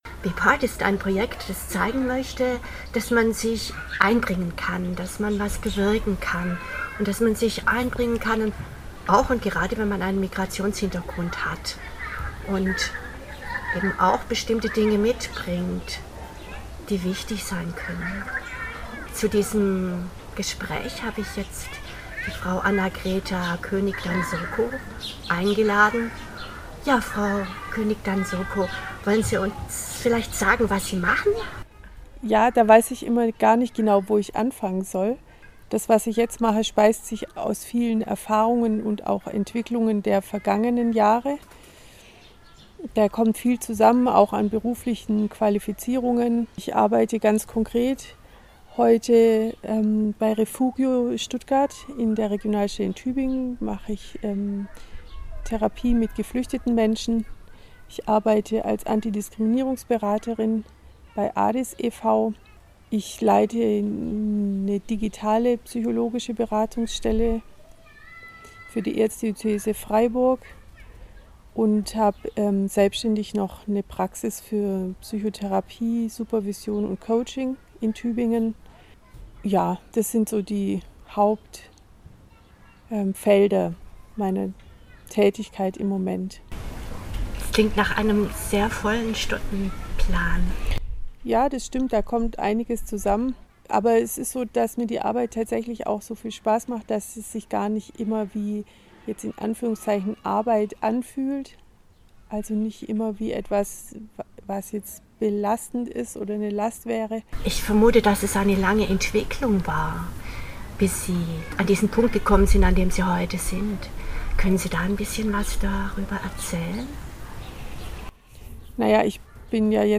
In ihrem Garten sprachen wir in diesem Sommer über ihren Werdegang